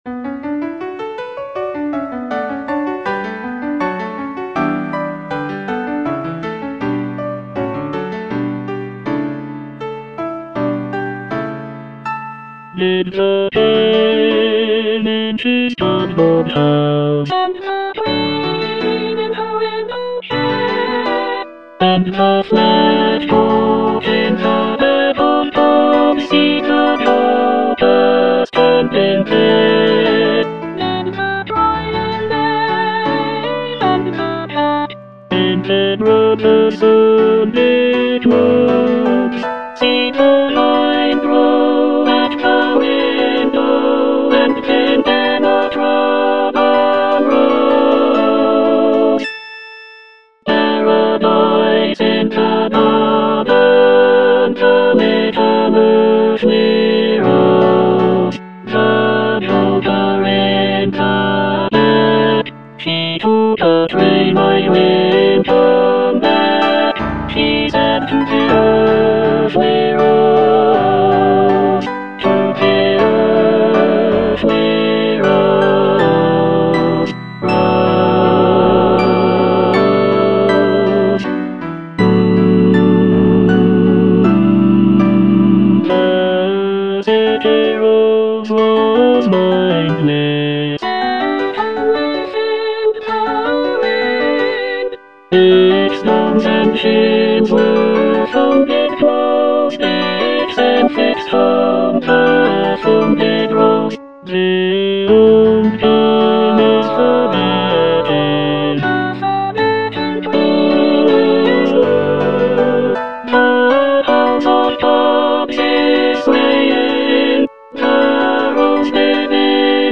Tenor II (Emphasised voice and other voices)
choral work
With its lush textures and haunting melodies